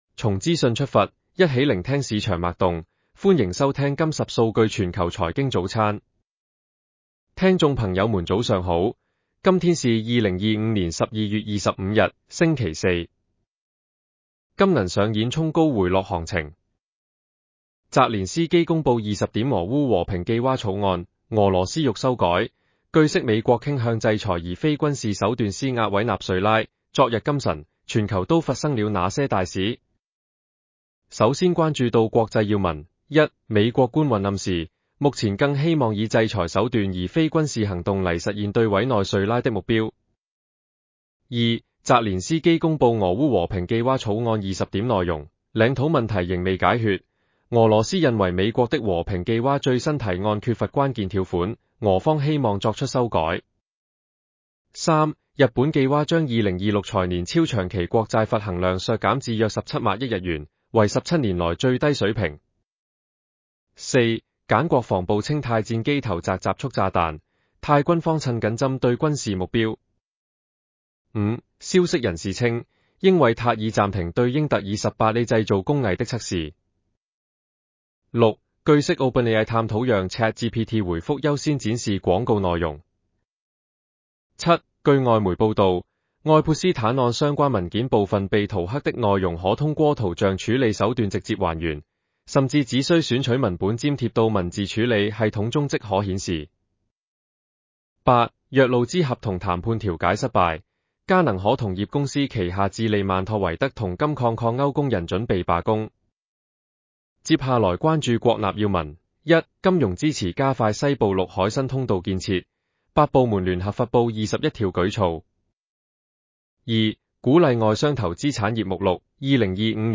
粤语版 下载mp3